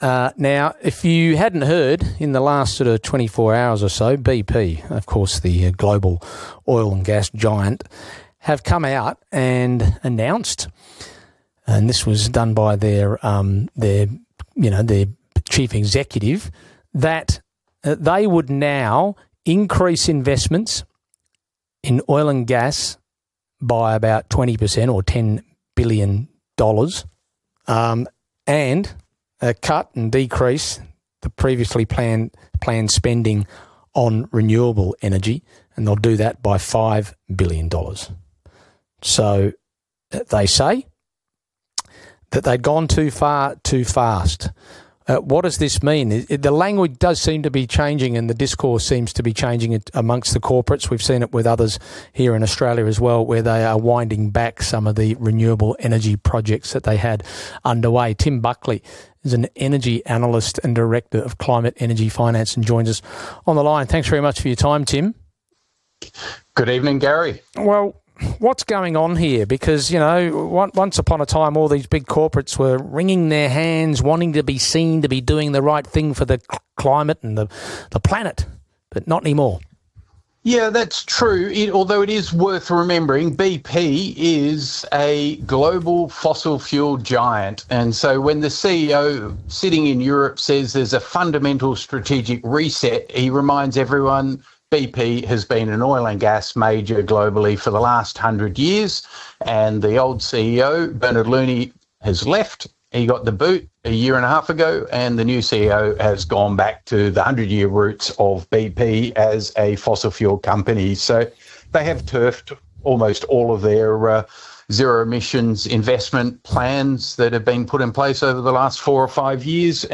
ABC RADIO INTERVIEW